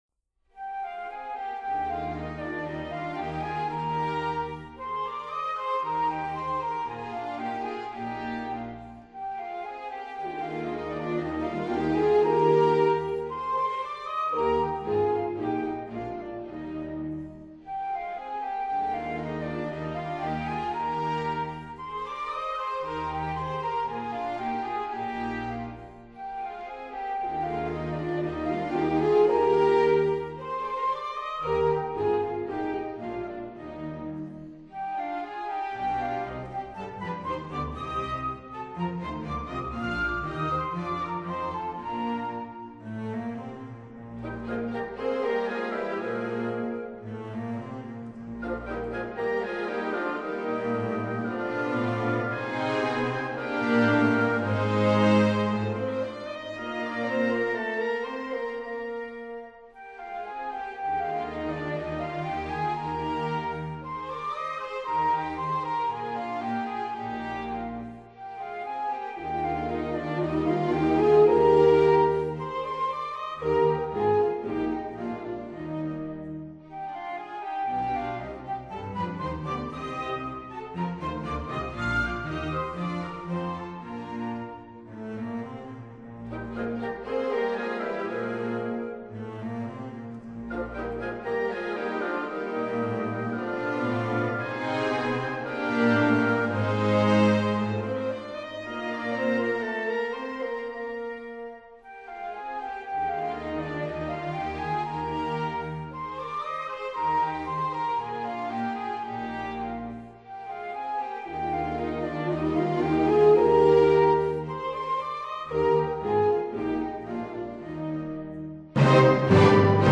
巴洛克音樂介紹